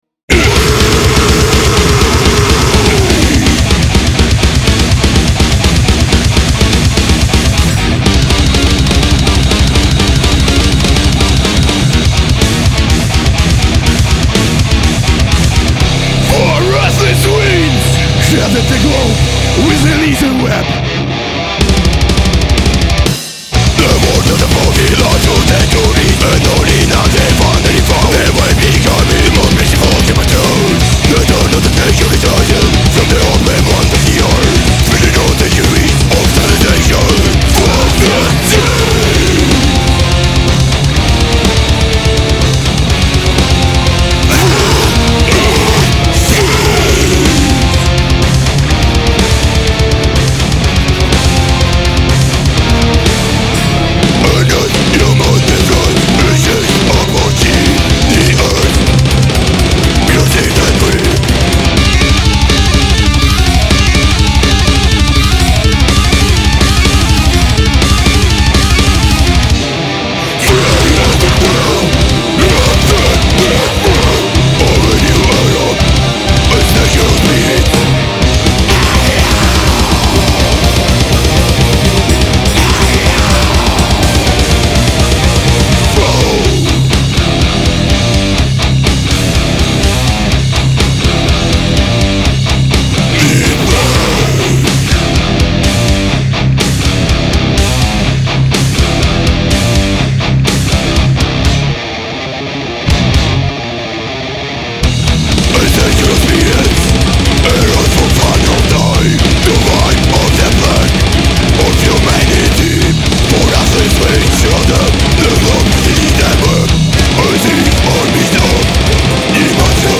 Диск записан в польской студии Hertz.